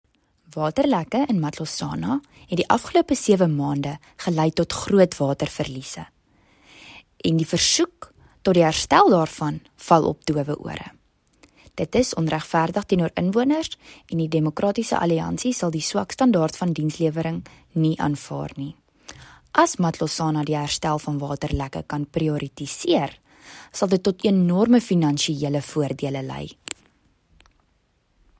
Note to Editors: Please find attached soundbites in